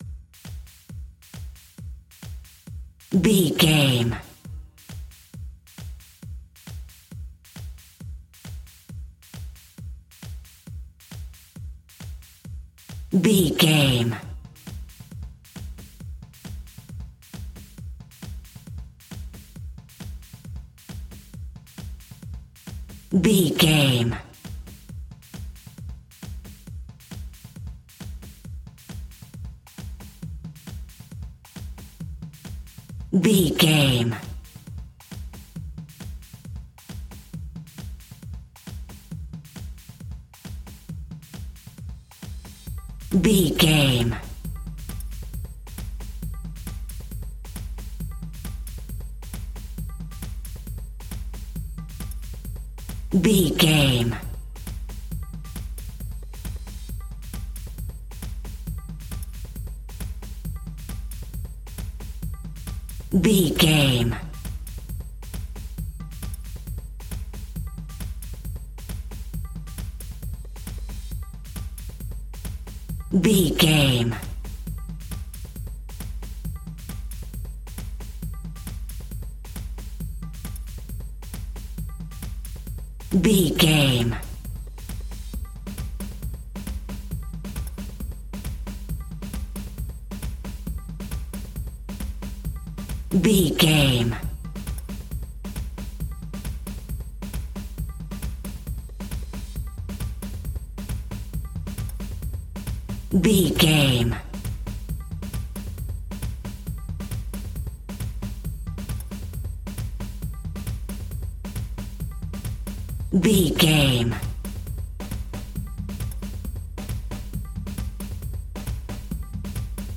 Atonal
Fast
World Music
ethnic percussion